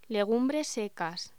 Locución: Legumbres secas